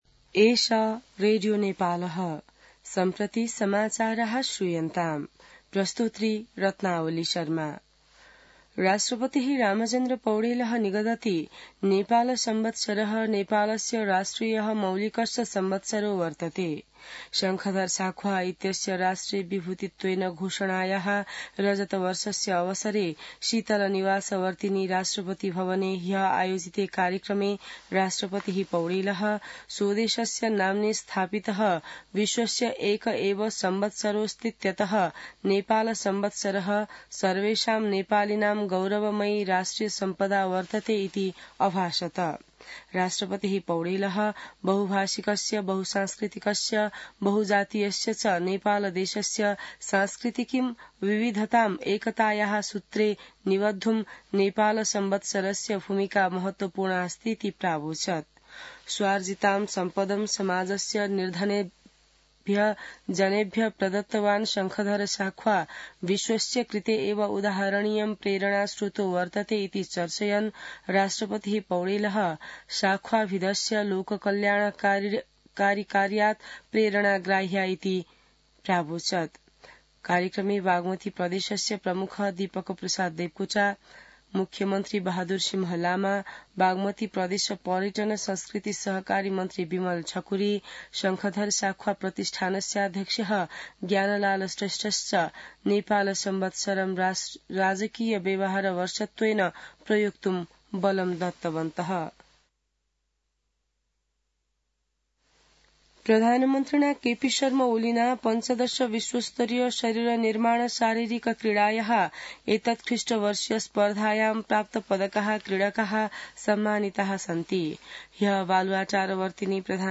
संस्कृत समाचार : ६ मंसिर , २०८१